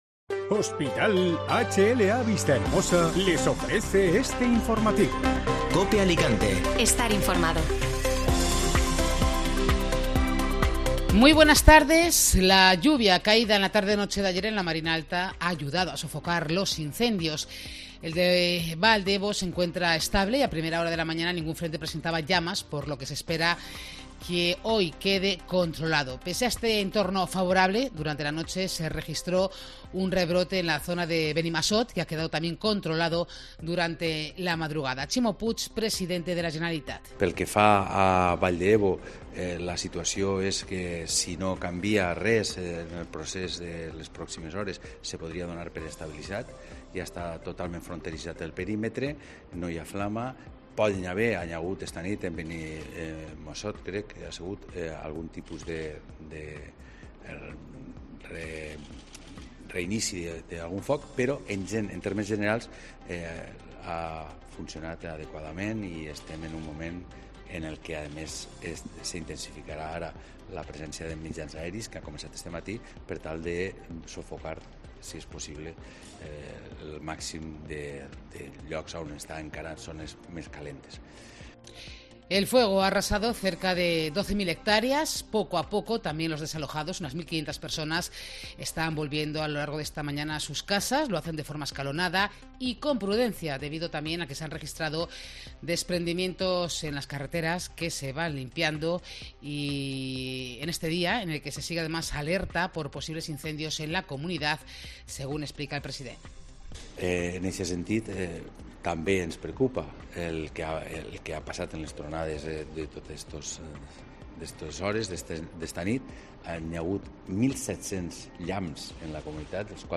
Informativo Mediodía Cope (Jueves 18 de Agosto)